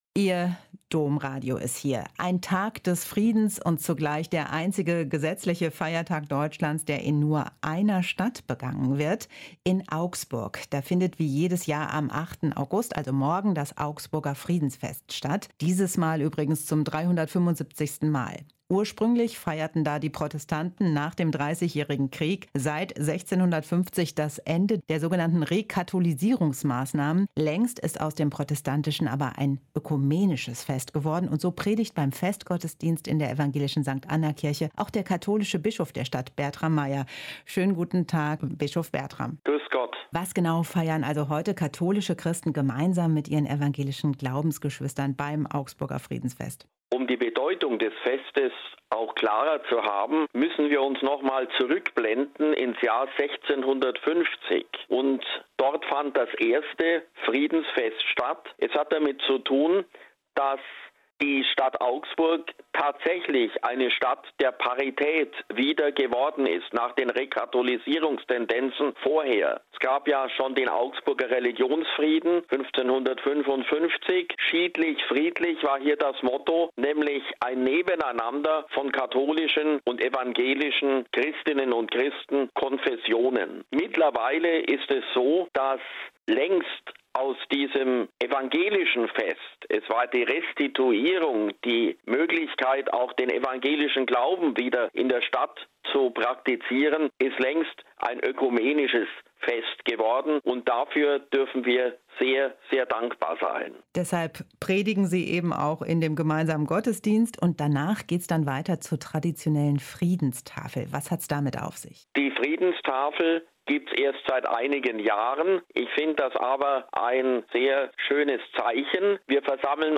Ein Interview mit Bertram Meier (Bischof von Augsburg)